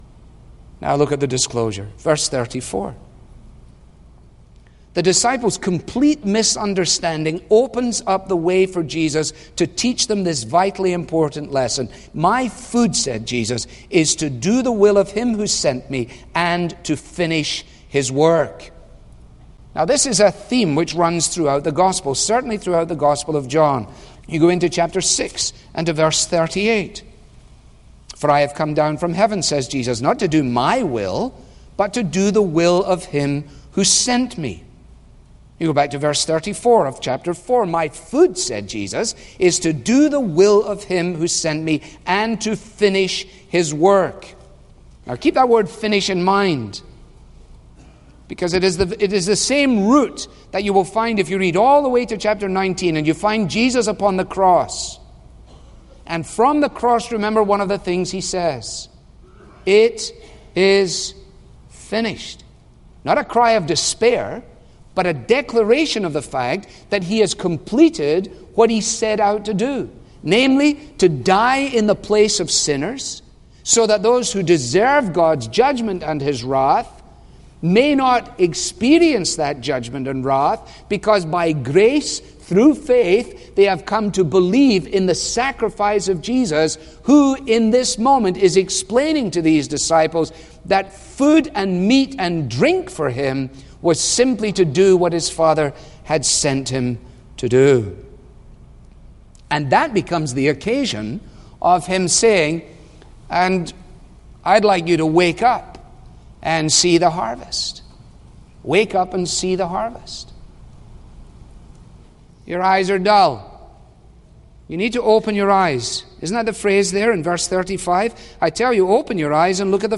Audio by Pastor Alistair Begg at Truth for Life, on our need as disciples to stay focused on doing the Will of God and being about His Work.